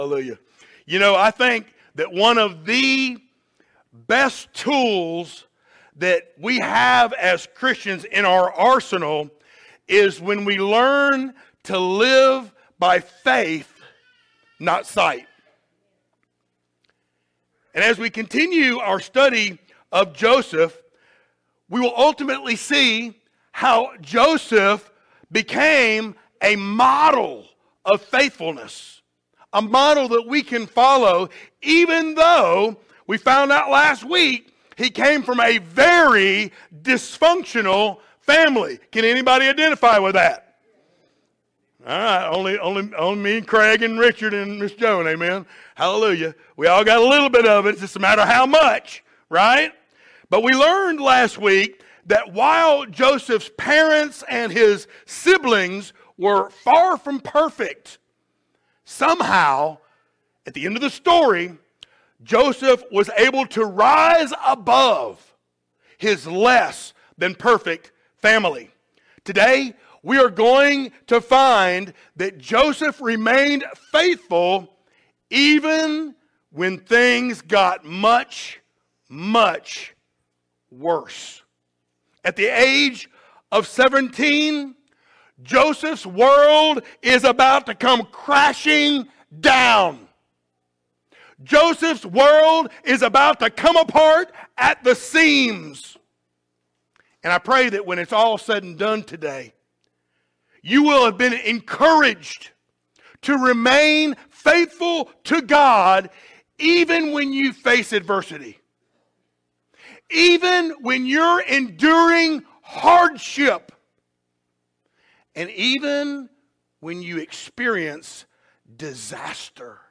Genesis 37:12-36 Service Type: Sunday Morning Download Files Notes Topics